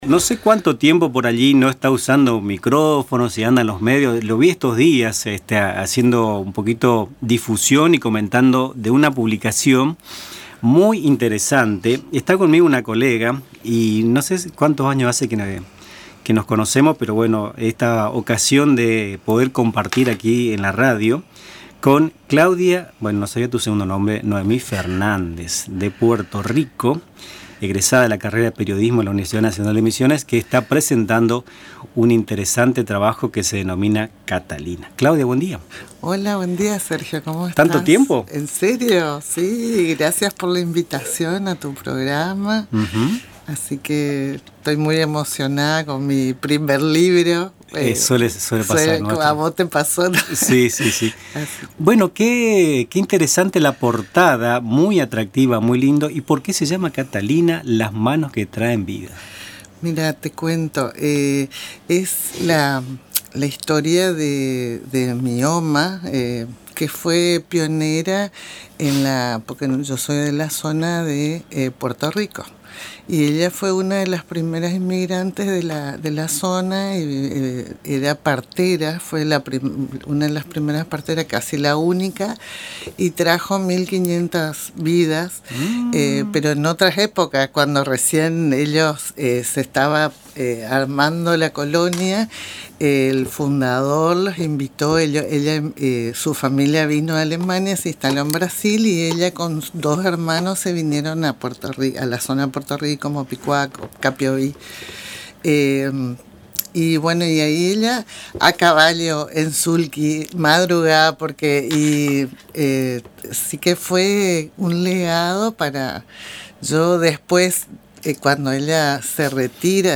Escuchá la entrevista, realizada en los estudios de Radio Tupambaé